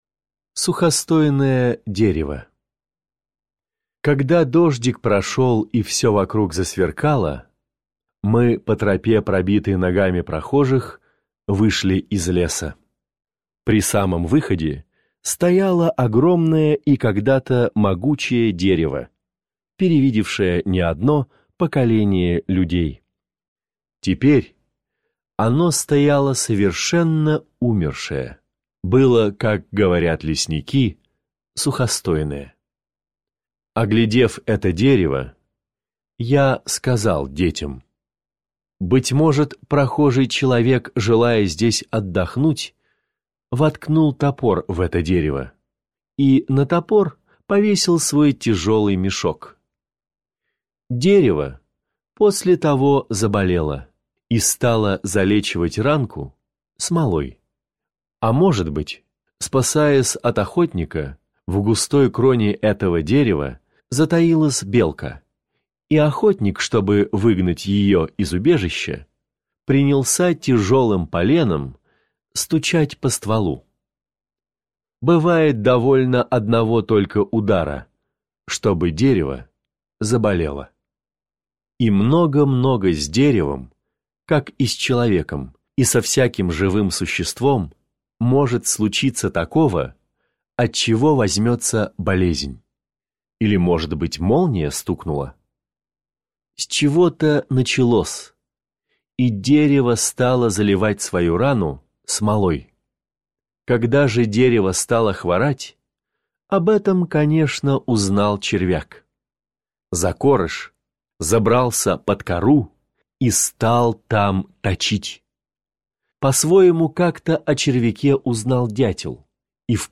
Сухостойное дерево - аудио рассказ Пришвина - слушать онлайн | Мишкины книжки
Аудиокнига в разделах